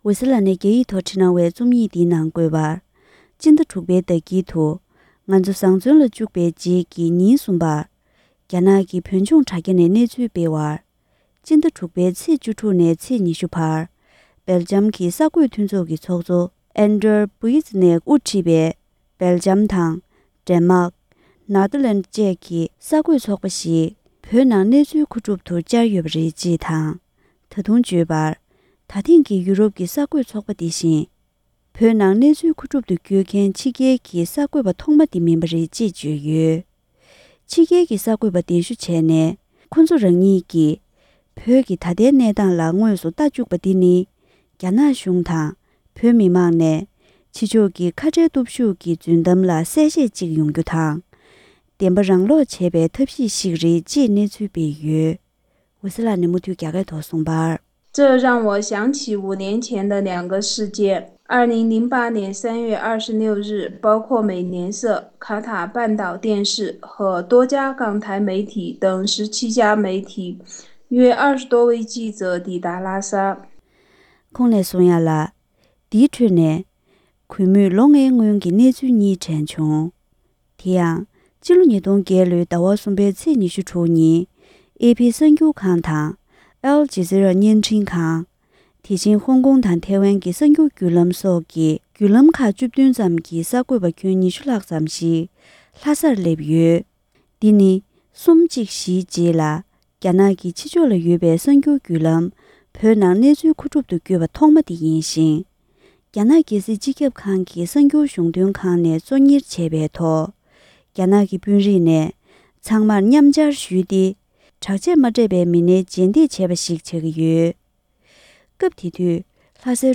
ཕབ་བསྒྱུར་སྙན་སྒྲོན་གནང་གི་རེད།།